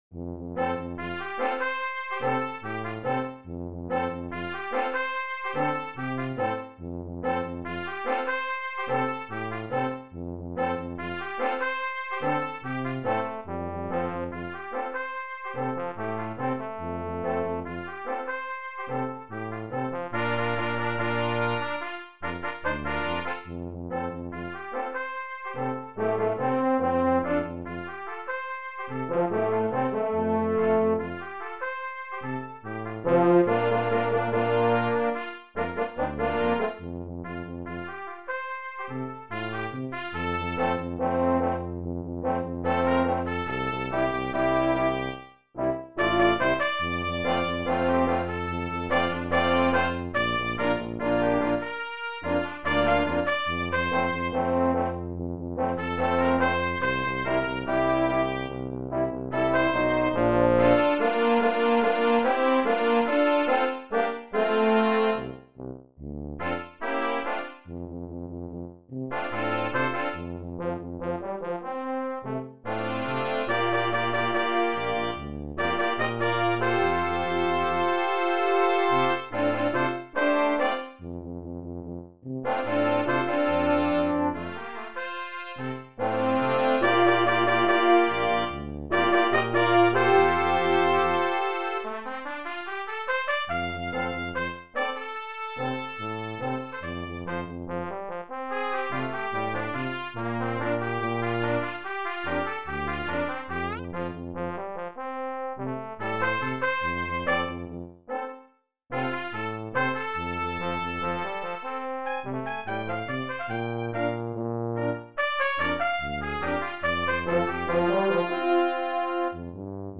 Brass Quintet